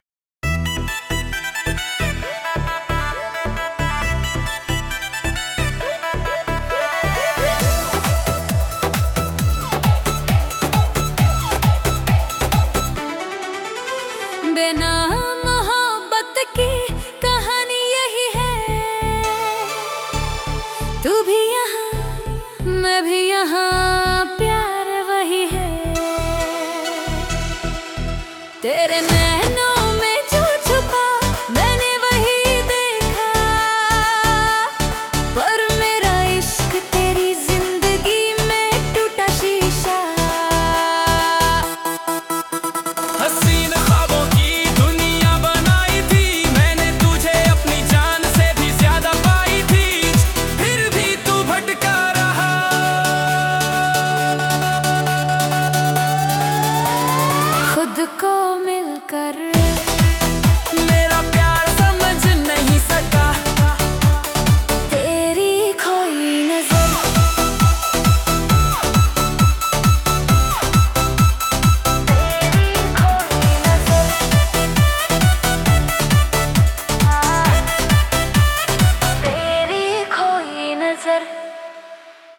Hindi Bubblegum Dance